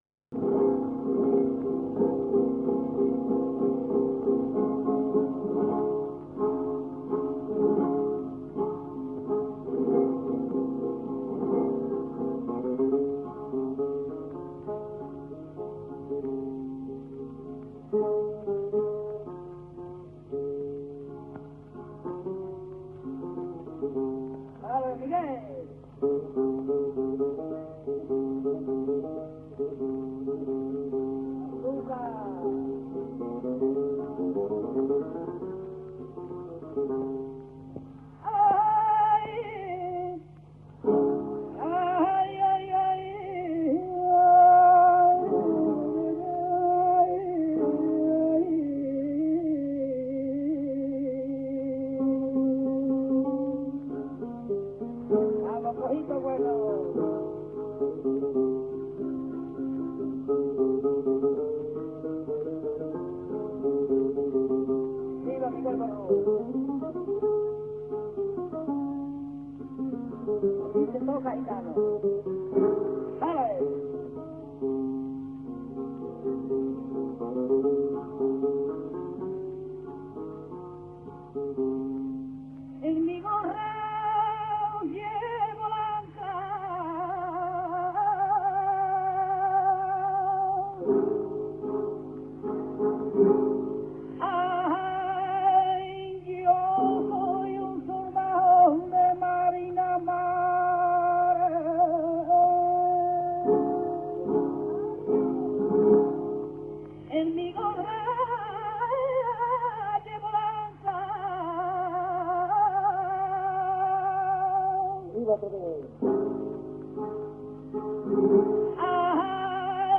Surtout, son style vocal singulier rend ses interprétations fondamentalement originales, quel que soit le modèle de référence : sur le plan mélodique, un usage intensif des notes de passage chromatiques, souvent accompagnées de portamentos ; sur le plan ornemental, un continuum vibrato élargi/mélismes, le passage de l’un aux autres étant souvent indiscernable — de plus, contrairement à l’usage, ses mélismes plongent fréquemment sous la note porteuse, et procèdent parfois par notes disjointes, tempérées ou non.
taranta_3_basilio_1923.mp3